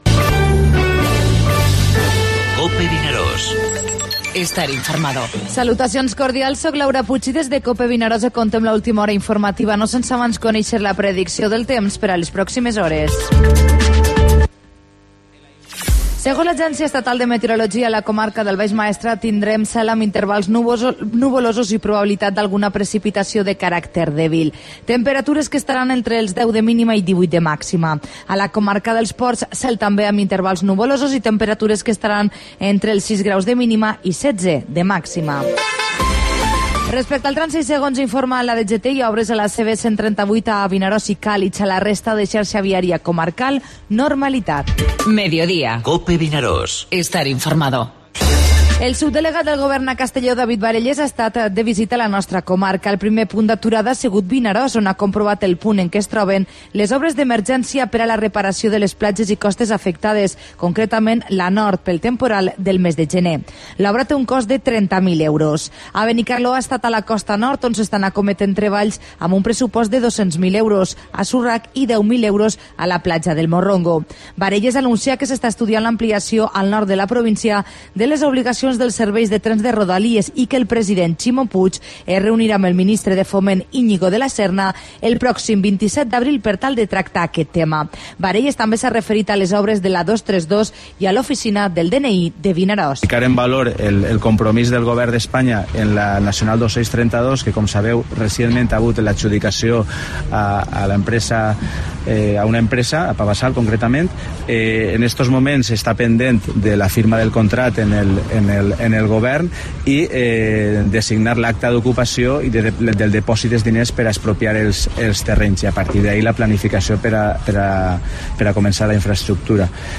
Informativo Mediodía COPE al Maestrat (31/03/17)